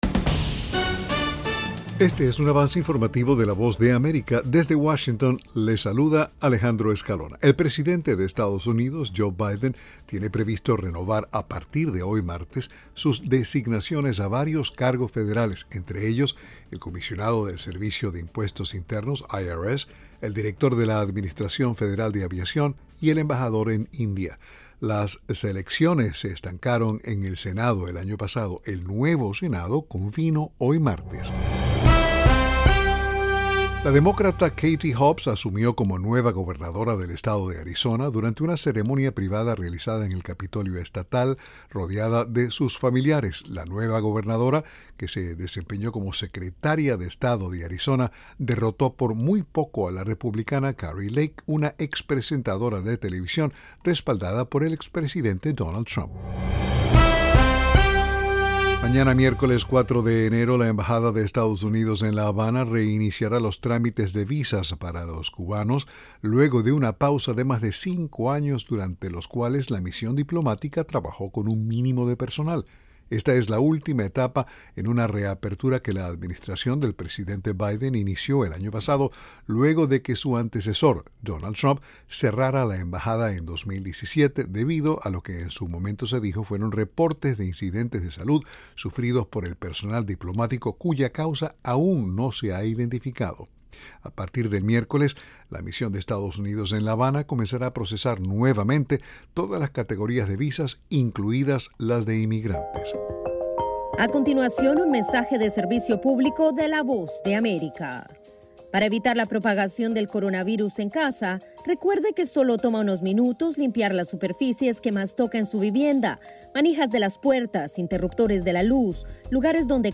Este es un avance informativo presentado por laVoz de América en Washington.